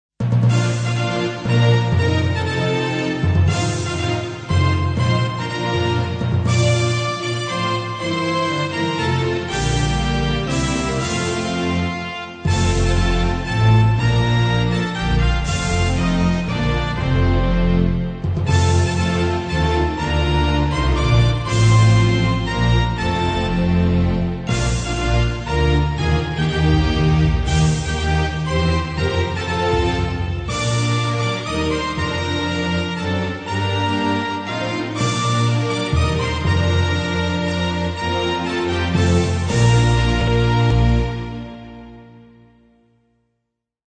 hymn Szwajcarii, napisany przez Alberta Zwyssig w XIX w.
hymn.mp3